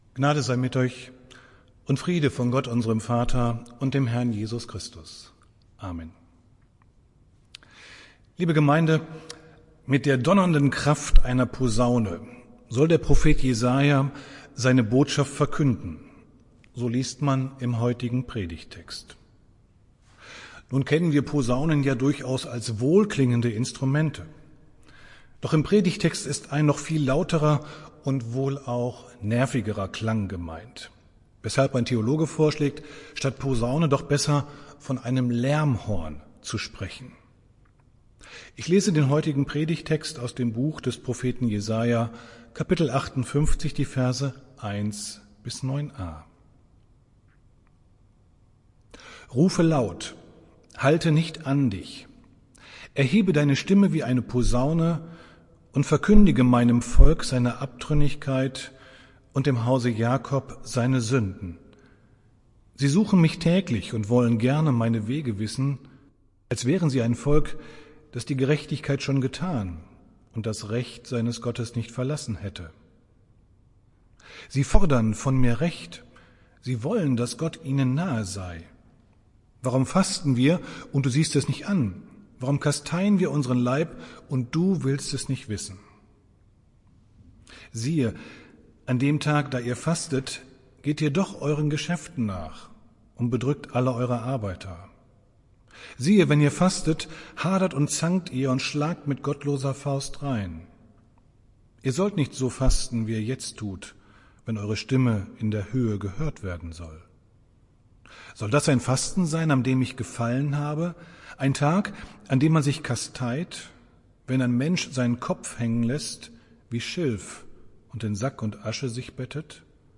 Predigt des Gottesdienstes aus der Zionskirche vom Sonntag, 14.02.2021